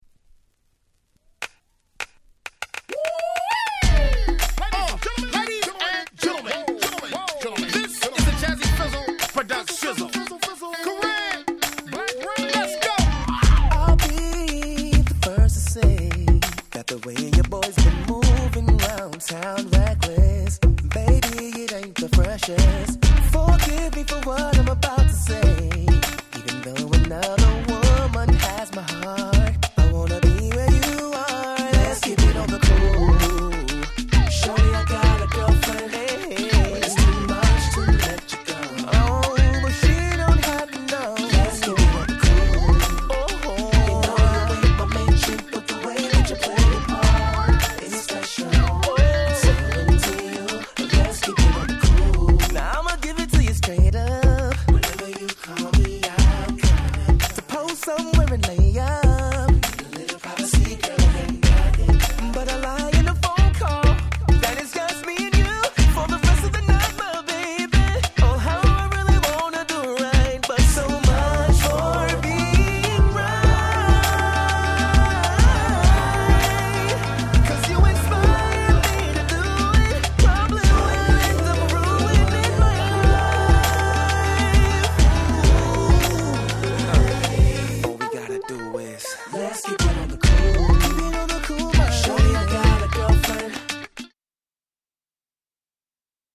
Nice Indie Soul !!